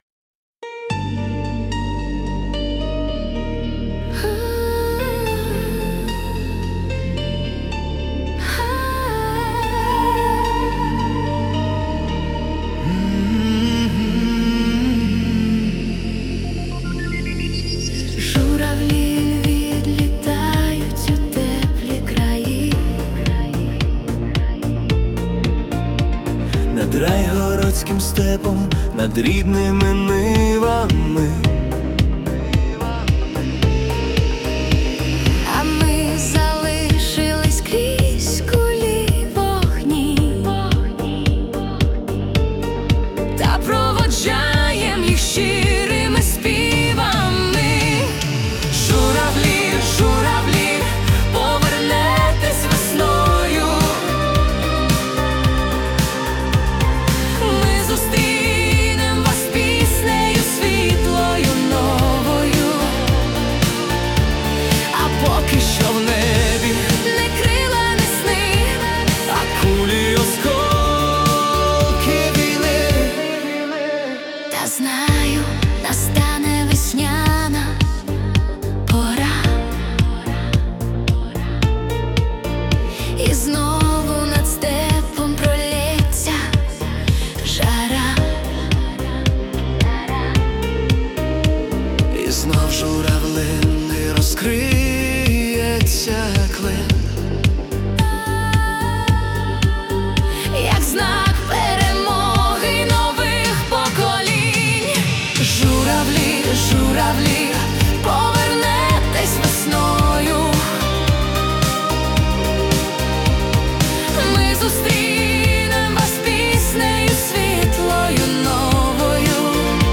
Synth-Pop Ballad (80s Style)